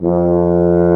BRS TUBA F0G.wav